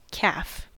[kʰæf]
En-us-calf.ogg.mp3